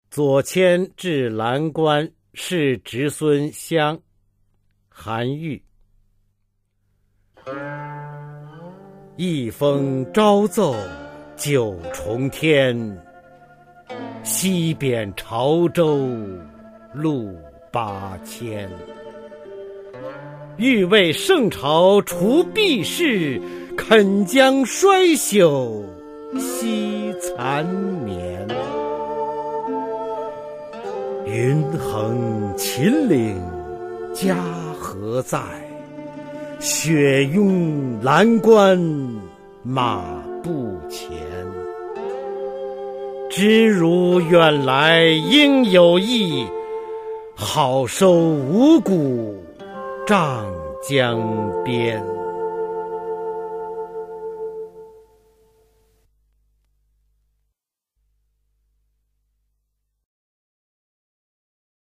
[隋唐诗词诵读]韩愈-左迁至蓝关示侄孙湘 古诗文诵读